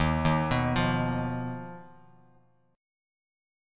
D#sus2#5 chord